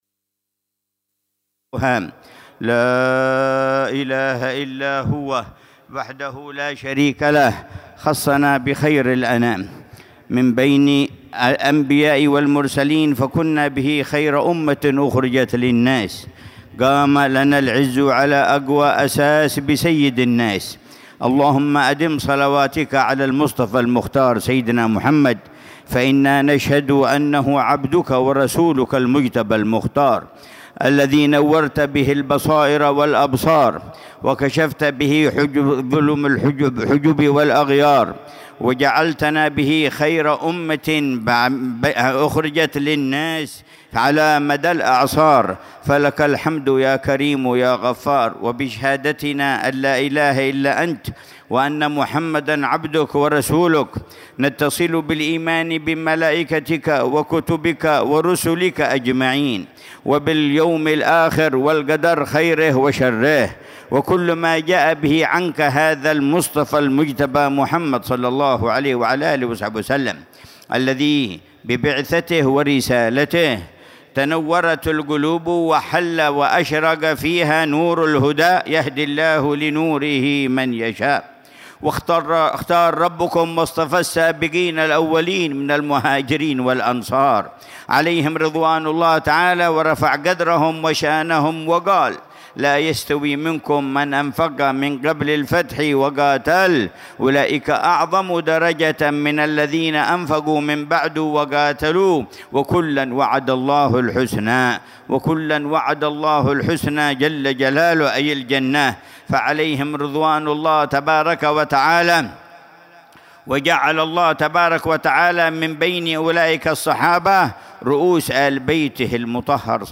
مذاكرة العلامة الحبيب عمر بن محمد بن حفيظ في مجلس الوعظ والتذكير في زيارة الإمام عبد الله بن علوي الحداد للنبي هود عليه السلام، شرق وادي حضرموت، ضحى السبت 9 شعبان 1446هـ بعنوان: